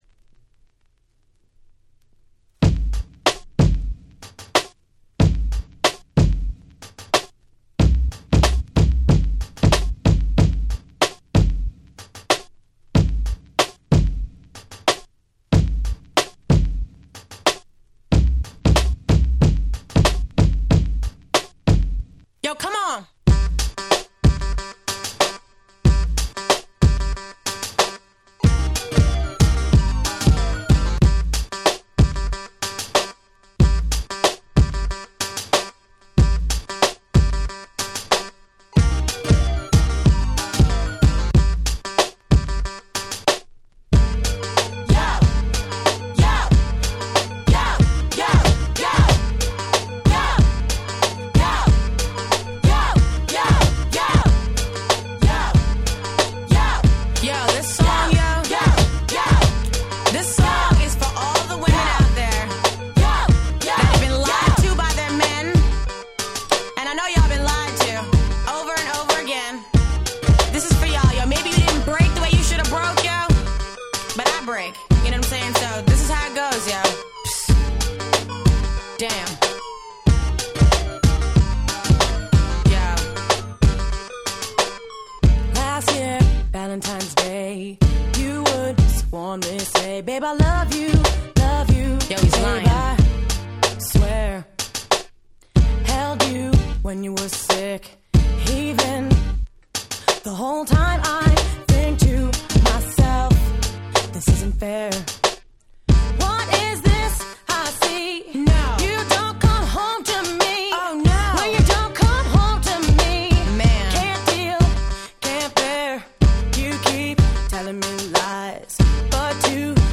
Late 90's R&B Classic !!
スーパークラシック。